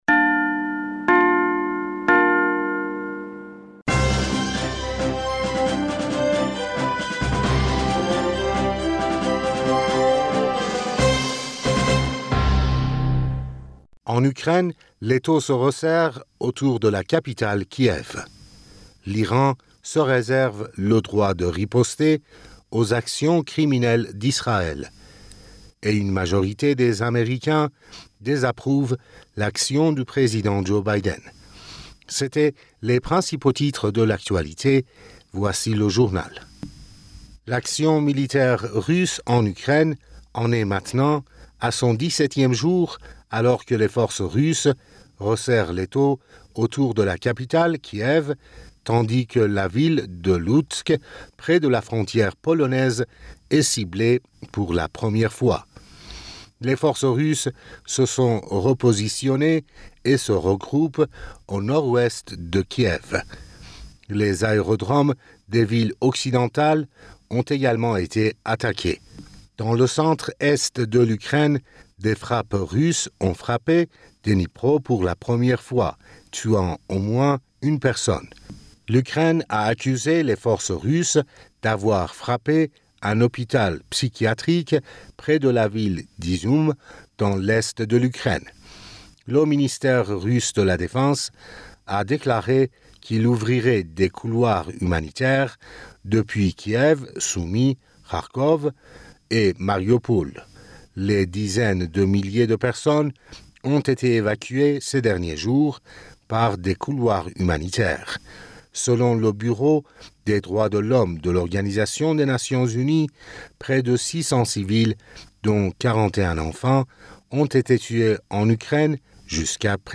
Bulletin d'information Du 12 Mars 2022